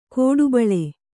♪ kōḍu baḷe